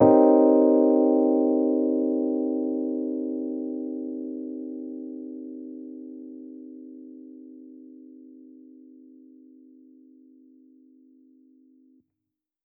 Index of /musicradar/jazz-keys-samples/Chord Hits/Electric Piano 2
JK_ElPiano2_Chord-Cm7b9.wav